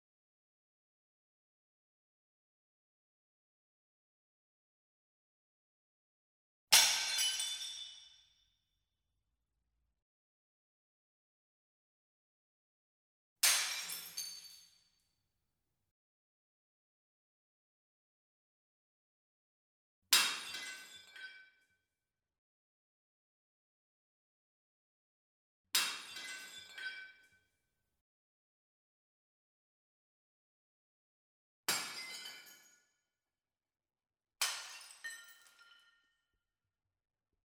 As a composite recording of plates being smashed repeatedly, bottom’s up! – sound installation – explores what Allen Kaprow posited as the ‘indistinct’ line between art and life (Kaprow 1966). Examining the impact and strength of a single, “commonplace” – albeit irksome and raucous – sound in an unprecedented, yet not altogether unforeseen environment.
USE-Plates-Documentation-mixdown.mp3